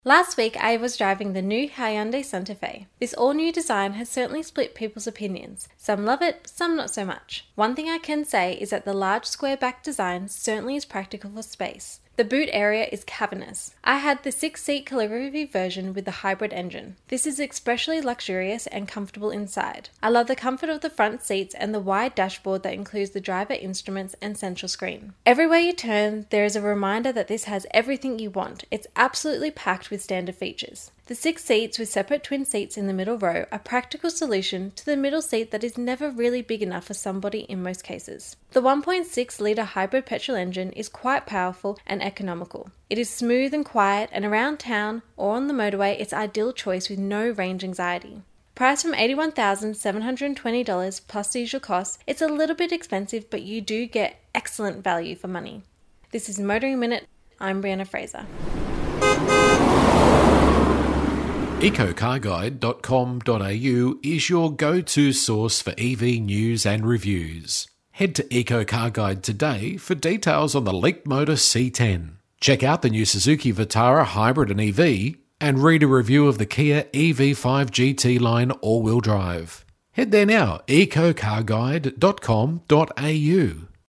Motoring Minute is heard around Australia every day on over 120 radio channels.